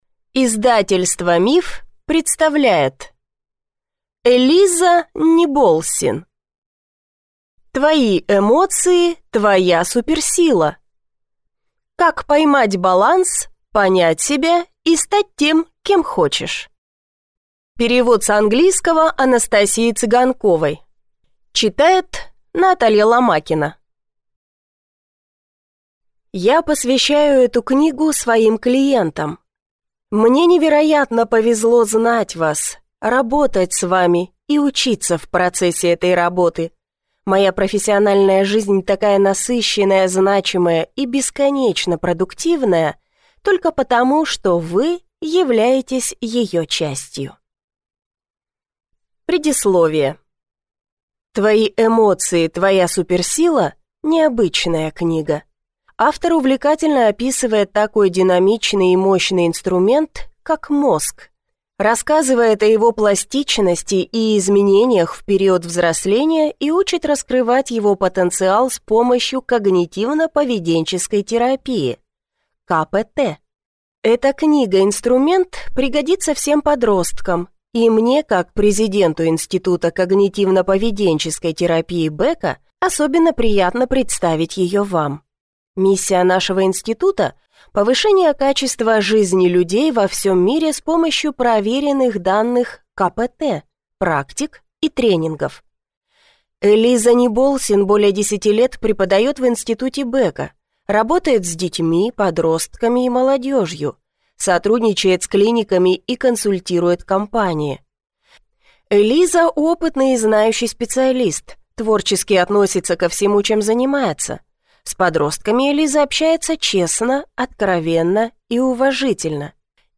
Аудиокнига Твои эмоции – твоя суперсила. Как поймать баланс, понять себя и стать тем, кем хочешь | Библиотека аудиокниг